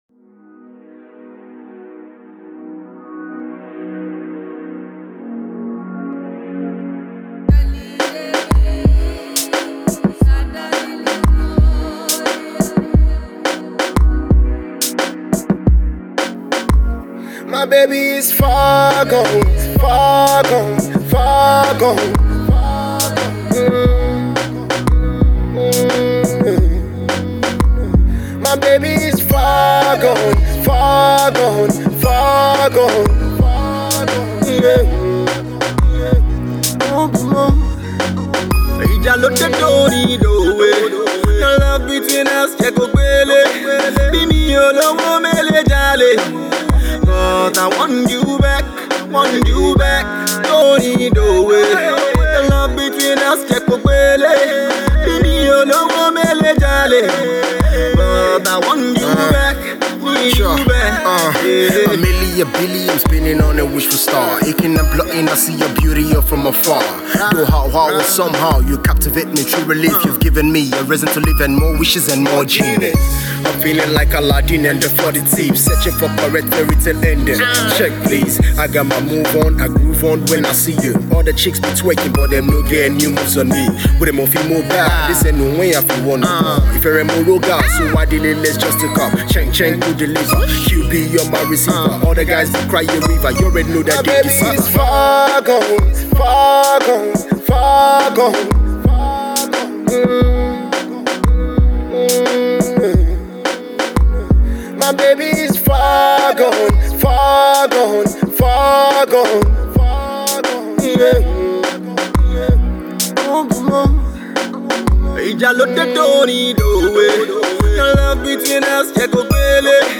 Street Blues
soulful ballad
additional vocals